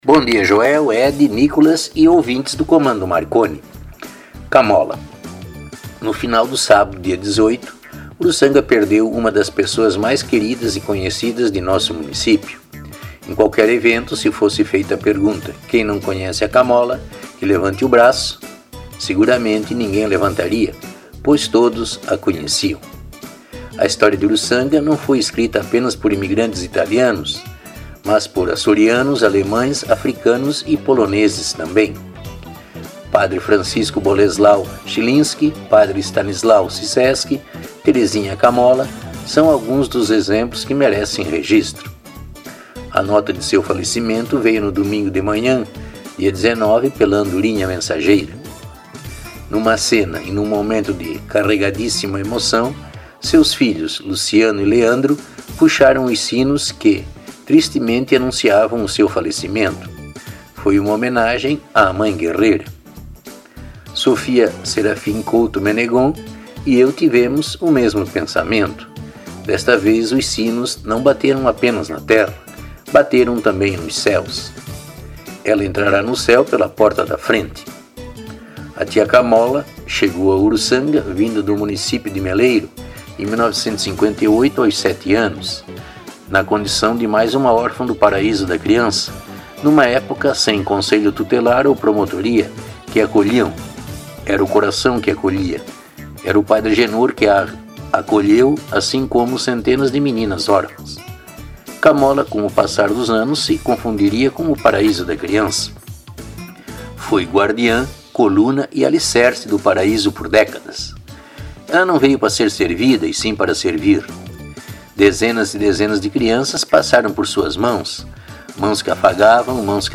A atração é apresentada de modo espirituoso e com certas doses de humorismo e irreverência, além de leves pitadas de ironia quando necessárias.